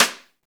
SNR XXSTI0PL.wav